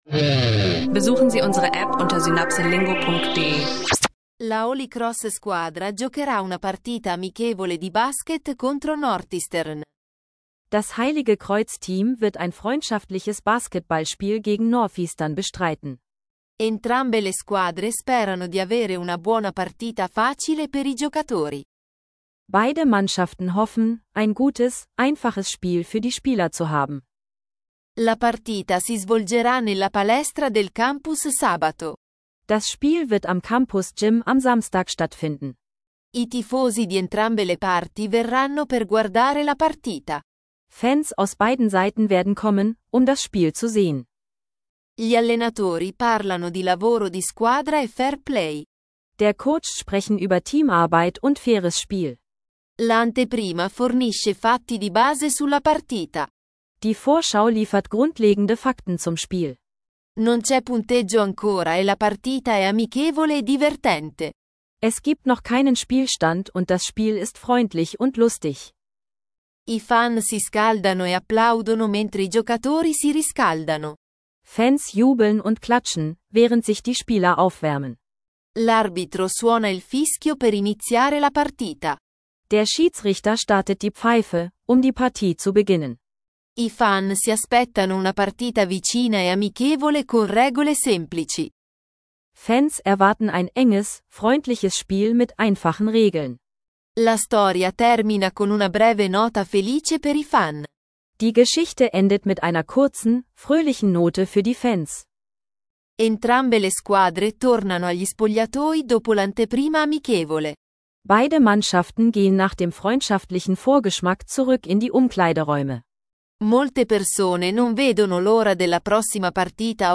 Ein anfängerfreundlicher Italienisch-Sprachkurs mit kurzen, klaren